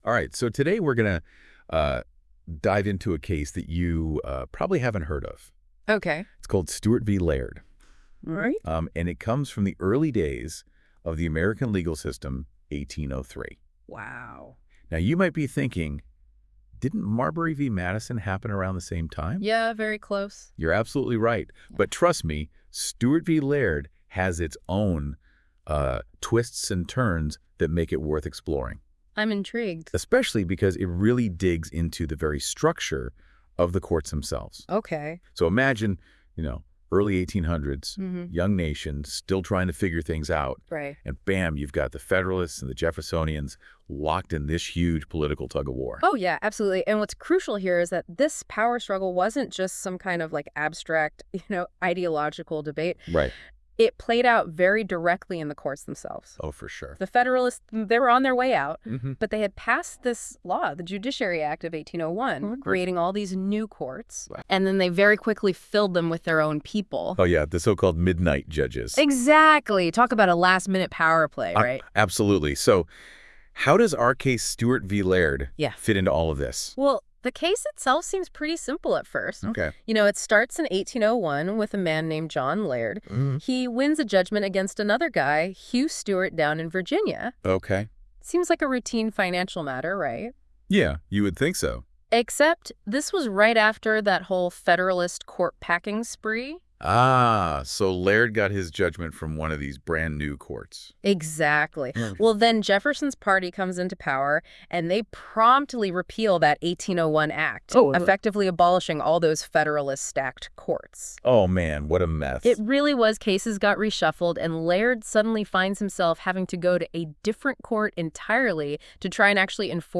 Listen to an audio breakdown of Stuart v. Laird.